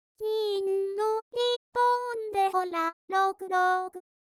そこで、ダイナミックスを制御して、２つの音符にまたがってディケイを掛けます。
ダイナミックスを制御して、２つの音を１つと考えてディケイを掛ける